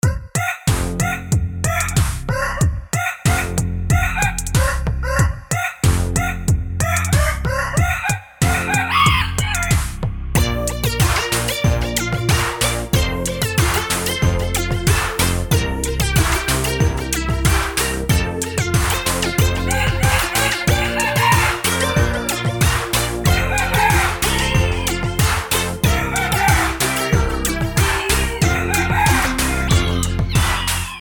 FREE funny alarm clock sound RINGTONE for your phone